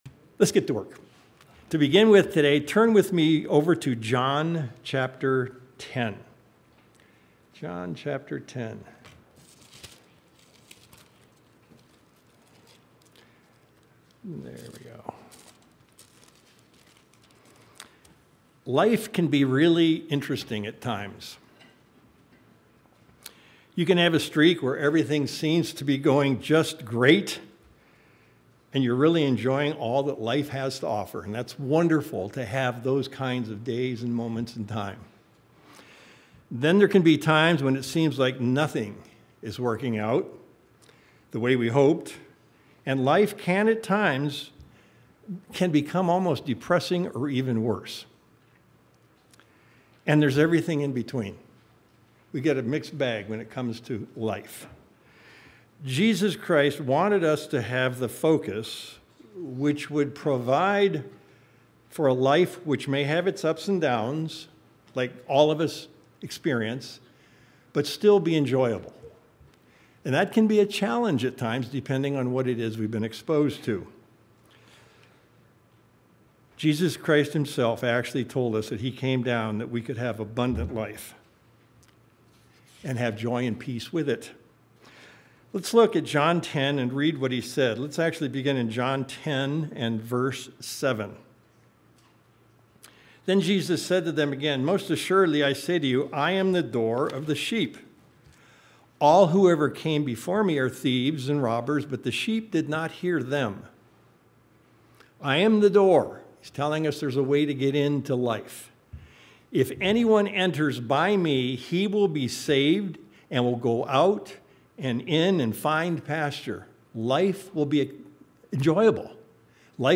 Paul was very direct in showing us how all of our behaviors matter. We'll look at this concept in today's sermon